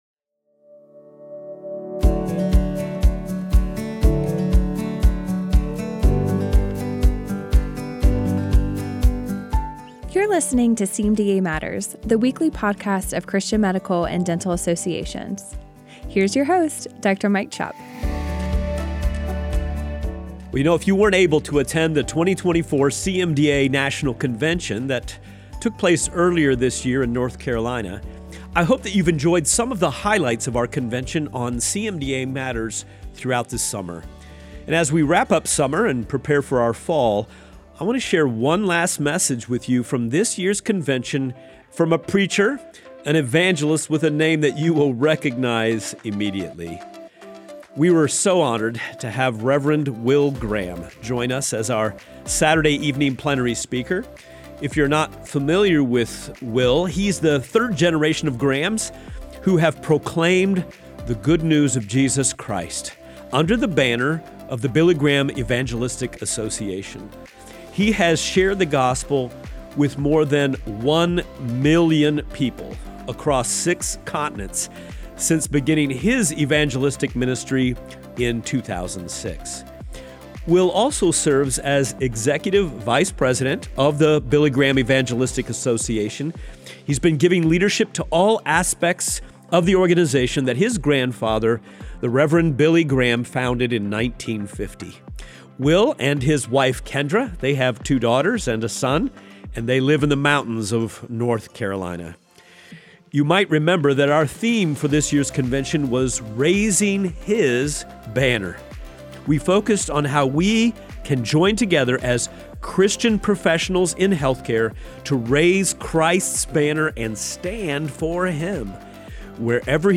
we share a special recording from the 2024 CMDA National Convention